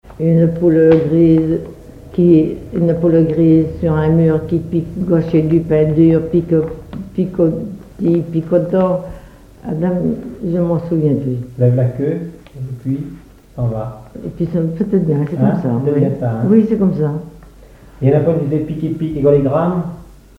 Enfantines - rondes et jeux
collecte en Vendée
répertoire enfantin
Pièce musicale inédite